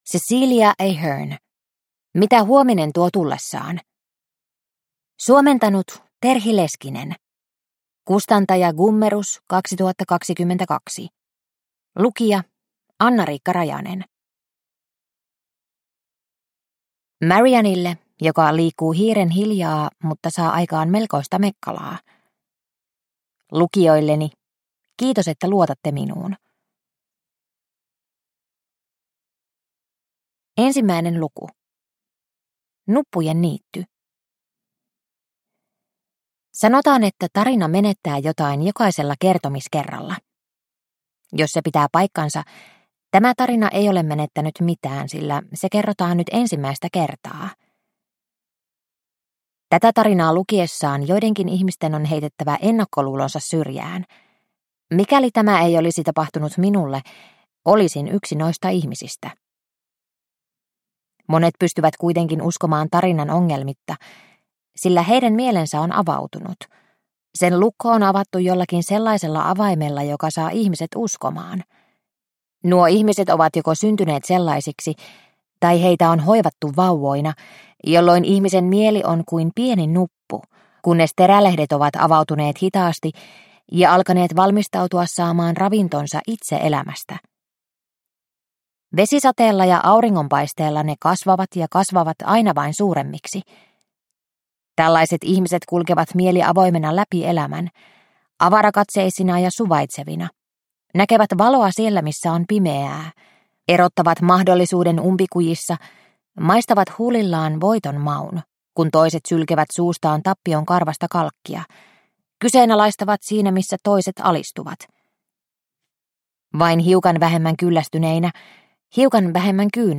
Mitä huominen tuo tullessaan – Ljudbok – Laddas ner